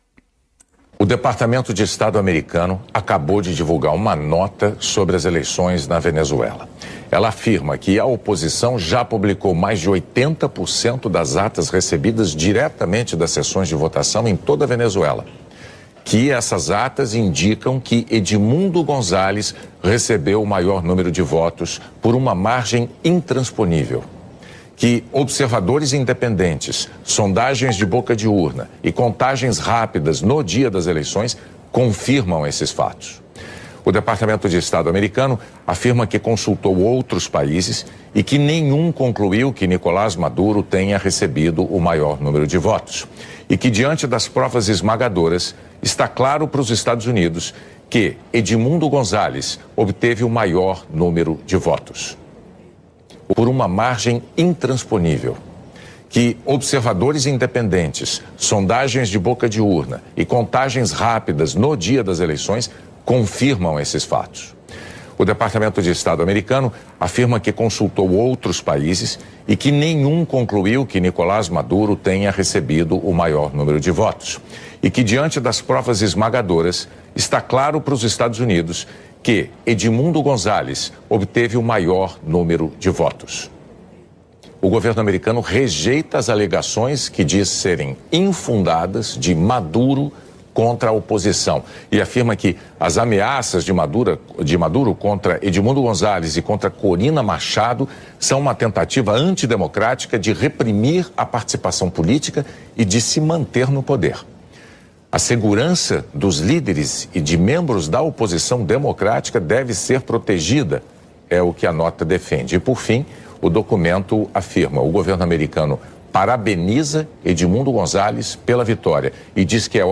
A cobertura desse desenrolar dos eventos foi um destaque no “Jornal Nacional” da Globo, apresentando a notícia como um marco crucial na política venezuelana, com o próprio William Bonner conduzindo a reportagem.